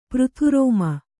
♪ přrhu rōma